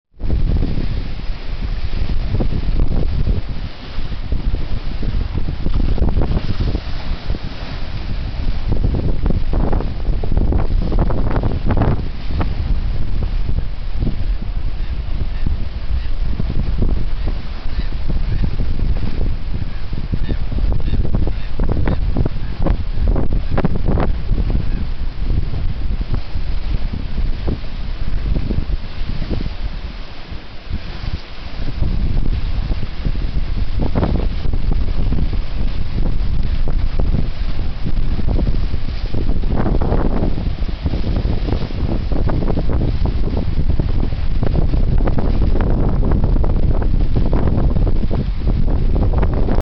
Choppy seas at Beresford Gap.